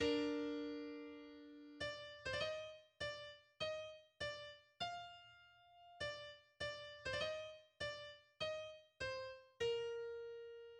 Форма симфония
Тональность си-бемоль мажор[1]
Симфония написана для двух гобоев, двух валторн и струнных.